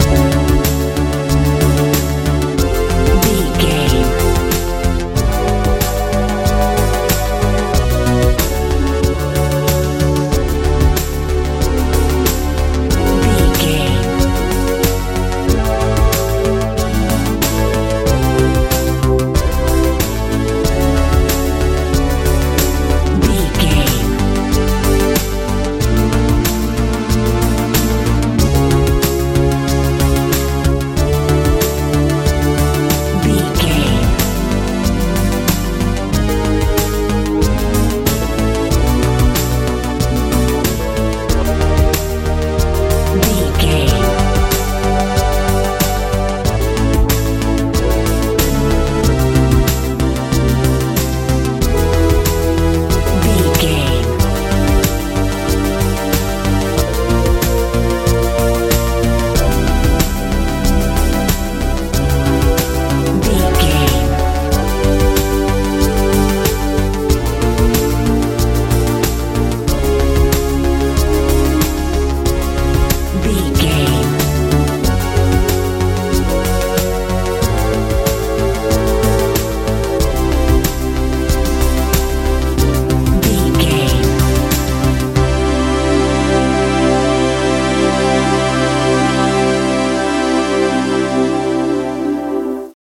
pop dance feel
Ionian/Major
F♯
peaceful
calm
synthesiser
bass guitar
drums
80s
suspense